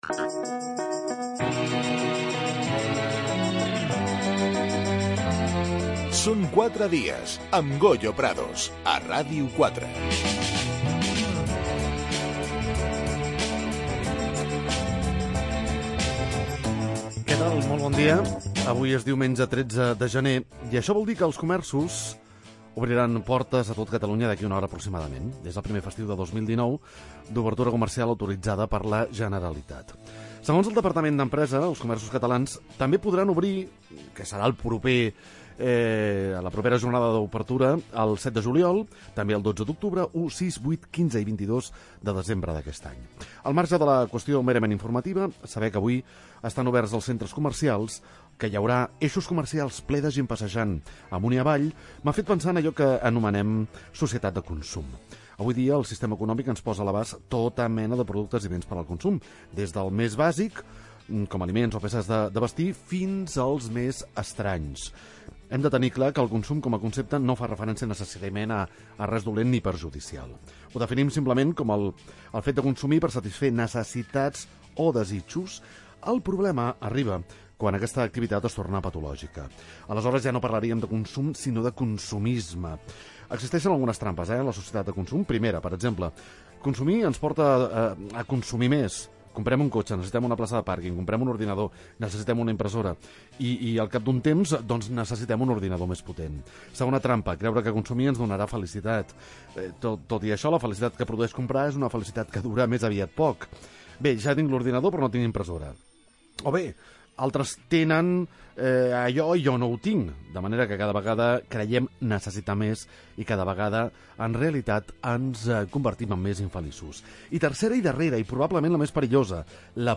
es entrevistado en el programa Són 4 dies